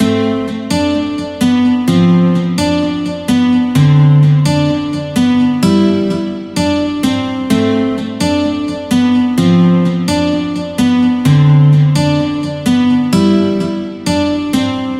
悠扬的吉他循环
Tag: 128 bpm Hip Hop Loops Guitar Acoustic Loops 2.53 MB wav Key : Unknown